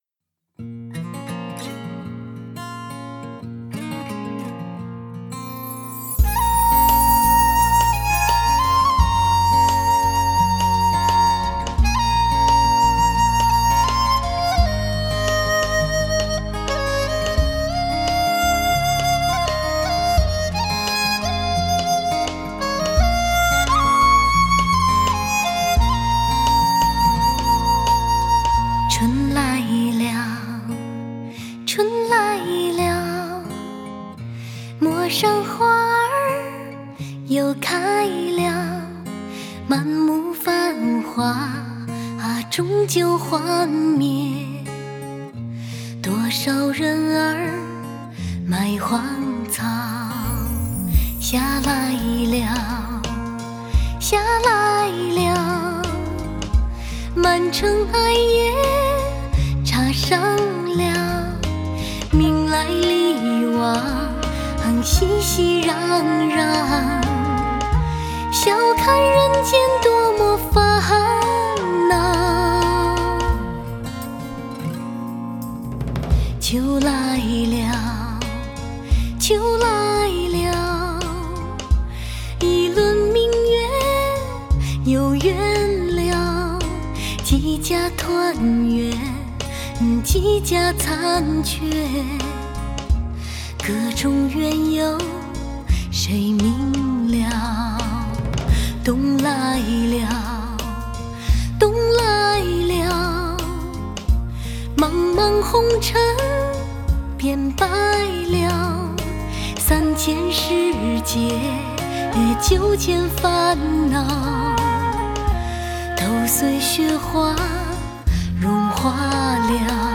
Жанр: Modern Traditional / Chinese pop